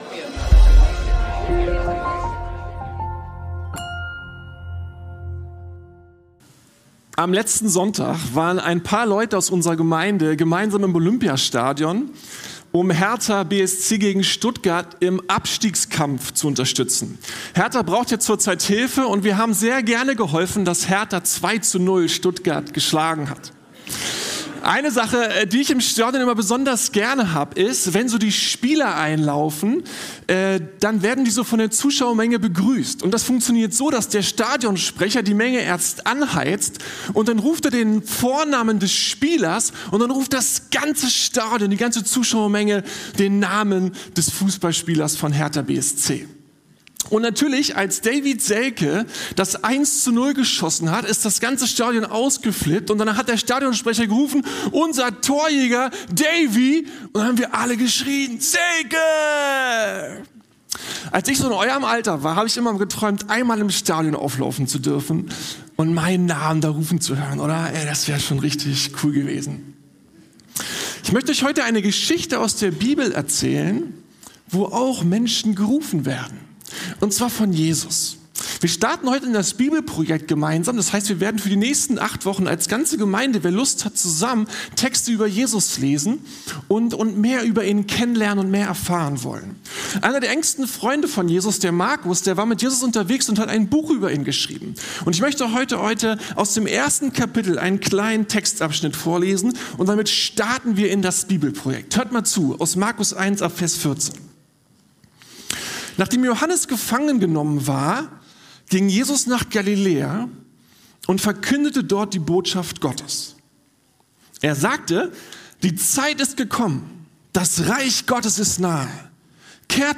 Die Ehrfurcht der Nachfolge ~ Predigten der LUKAS GEMEINDE Podcast